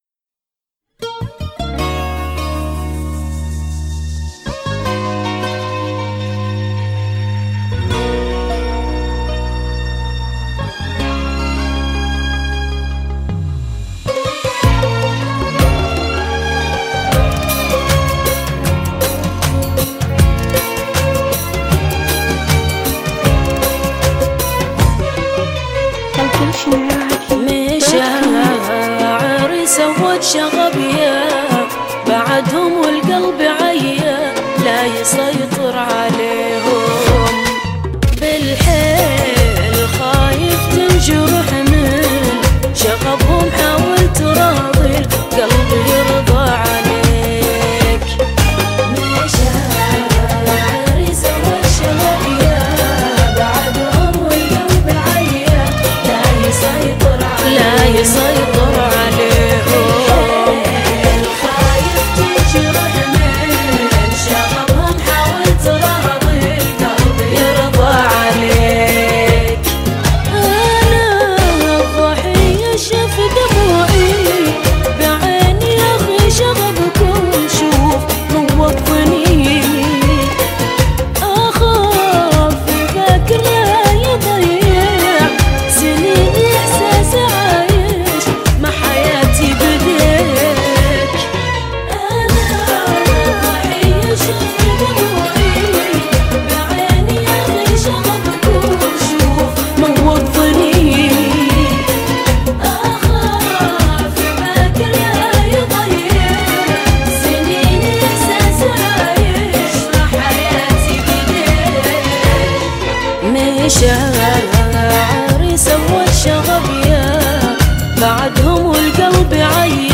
اغاني خليجيه